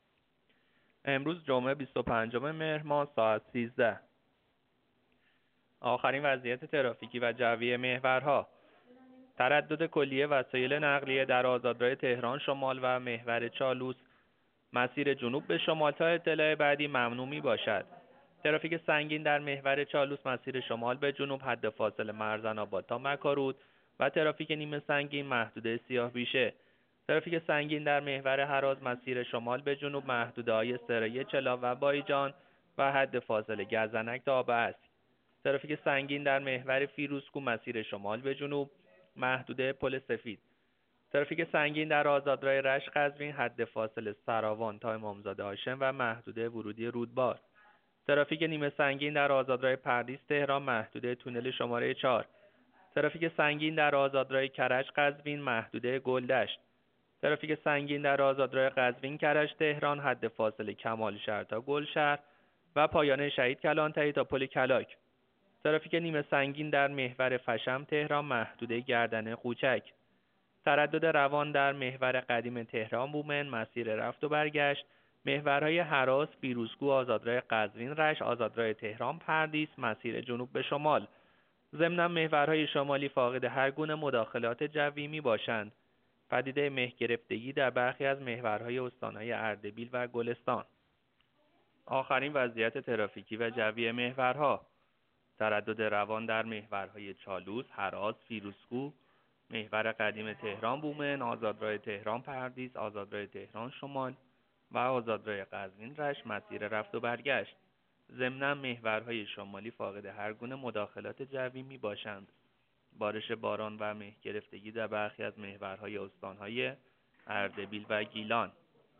گزارش رادیو اینترنتی از آخرین وضعیت ترافیکی جاده‌ها ساعت ۱۳ بیست‌وپنجم مهر؛